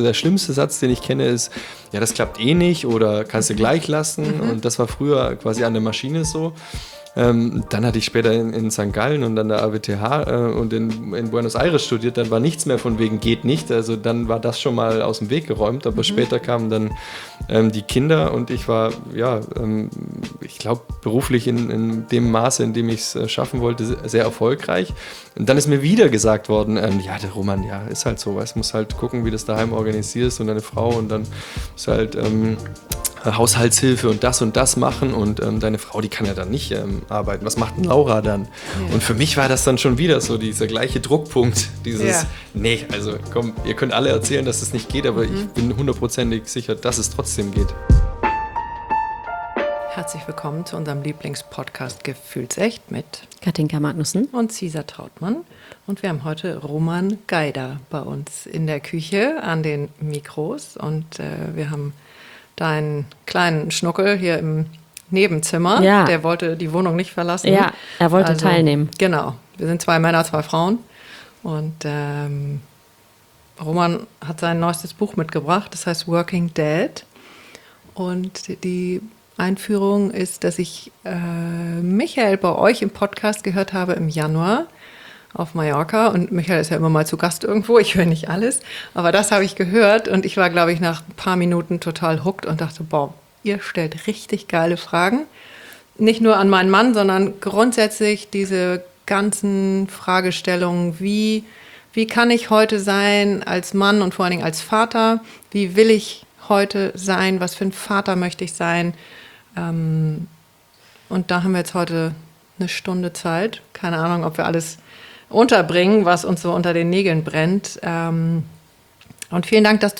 Die Idee ist simple: Frauen und auch Männer teilen ihr wahres Selbst und ihre echten Geschichten mit uns und unseren Hörern.
Wir laden euch in der nächsten Stunde an unseren Küchentisch ein und öffnen mit unserem Gast einen besonderen “safe space” um das zu erleben, was gefühlt werden will.